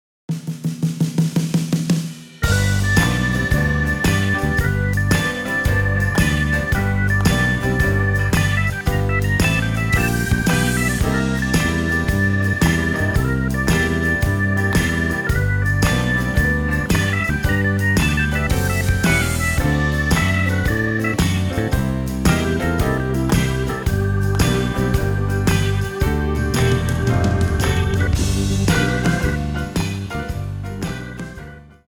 112 BPM